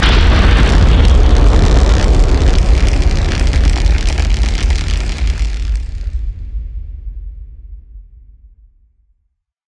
DischargeLarge1.ogg